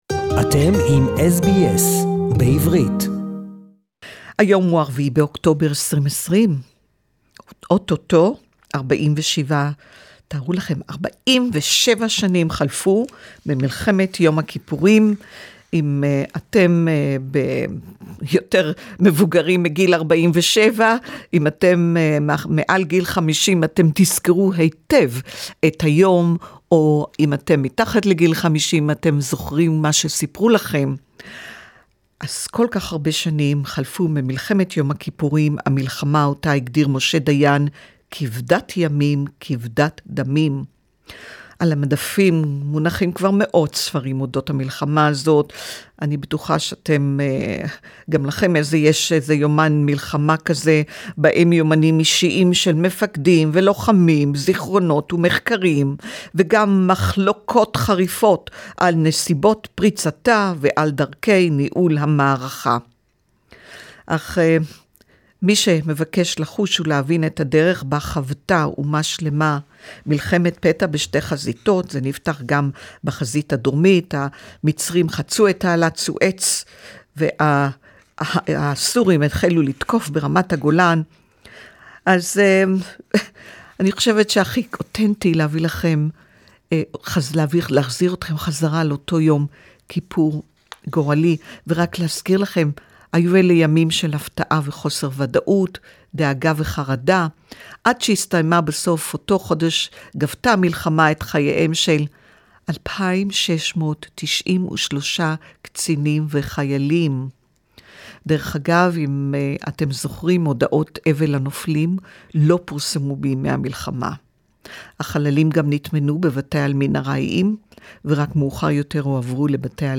47 years to Yom Kippur War...listen to original recordings including PM Golda Meir from 6.10.1973 (Hebrew)
It is interesting to look back 47 years and find out how the Israelis found out about the war on the day, on Yom Kippur 1973. I will bring you today original recordings from Kol Israel from the day, including the famous speech of the PM Golda Meir